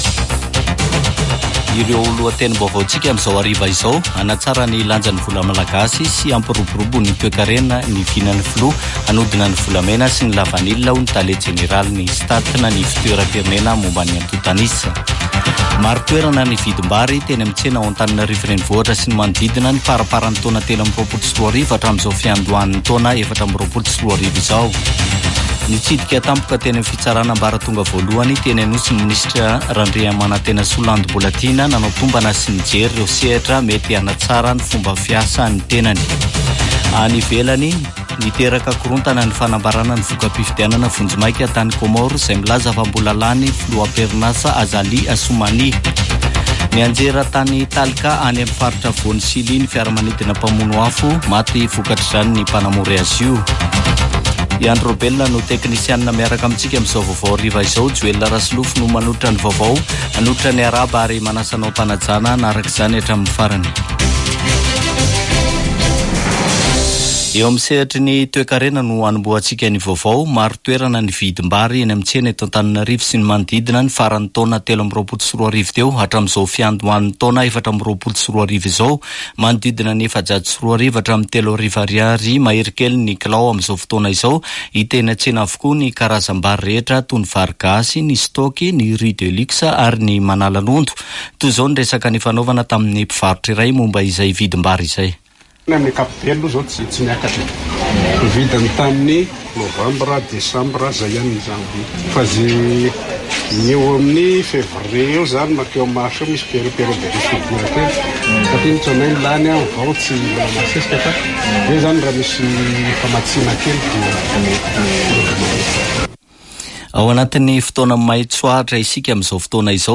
[Vaovao hariva] Alarobia 17 janoary 2024